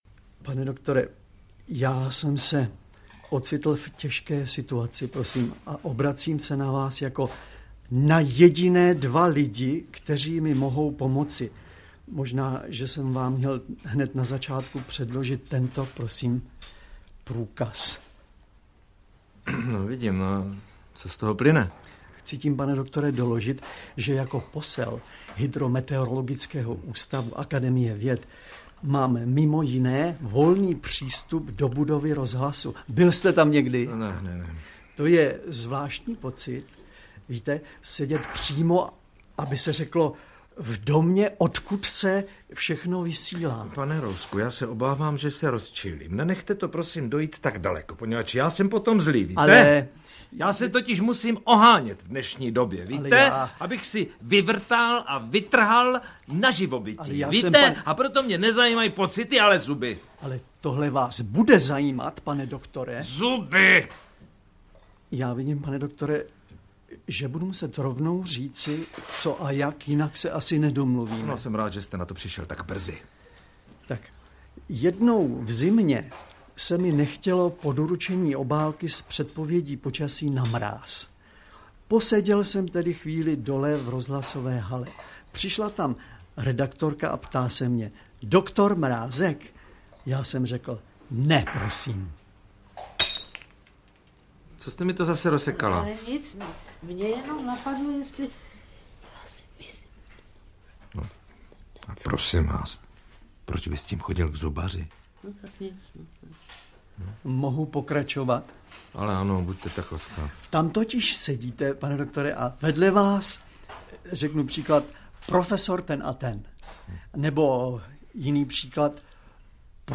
Audiokniha Posel hydrometeorologického ústavu, To jeli dva ve vlaku přináší dva 2 zábavné příběhy, které napsal Zdeněk Svěrák a uslyšíte v nich také takové bardy českého divadla, kterými byli Bohuš Záhorský, Rudolf Deyl ml. a Josef Abrhám.
Ukázka z knihy
• InterpretJosef Abrhám, Bohuš Záhorský, Zdeněk Svěrák, Rudolf Deyl ml.